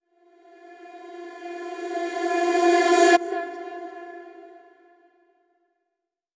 VEC3 Reverse FX
VEC3 FX Reverse 24.wav